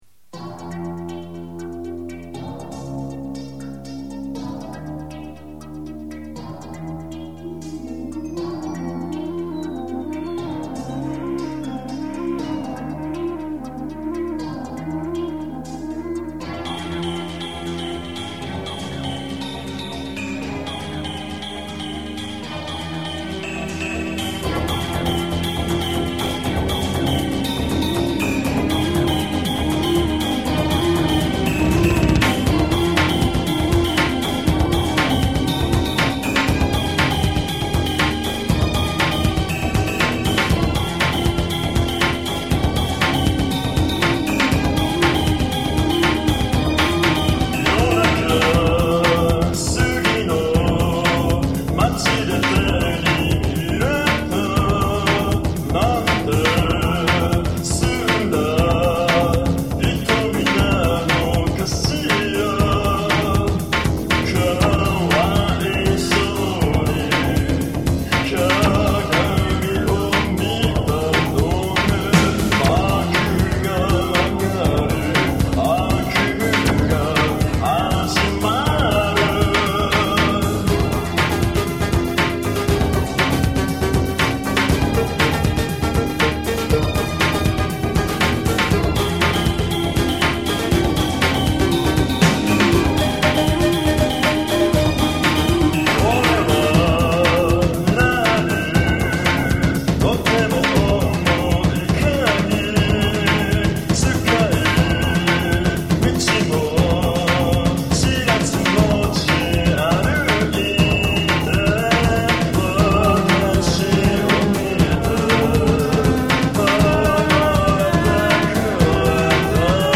version demotape
y la musica en si es bastante dark